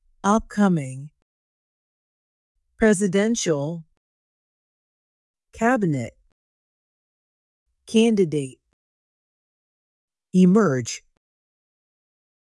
音声を再生し、強勢のある母音（＝大きな赤文字）を意識しながら次の手順で練習しましょう。
upcoming /ˈʌpˌkʌmɪŋ/（形）今度の、来たる
presidential /ˌprɛzɪˈdɛnʃəl/（形）大統領の、総裁の
Cabinet /ˈkæbɪnɪt/（名）内閣、閣僚
candidate /ˈkændɪˌdeɪt/（名）候補者、志願者
emerge /ɪˈmɜrdʒ/（動）現れる、浮上する